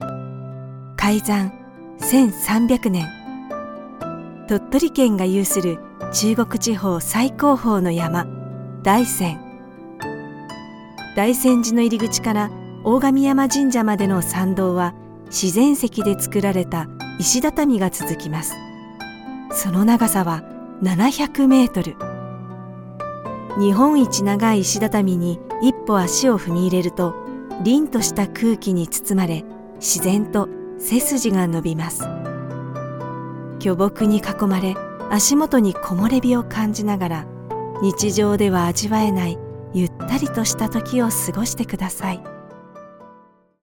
Kommerziell, Natürlich, Zuverlässig, Warm, Corporate
Audioguide
personable, persuasive, versatile, warm and authentic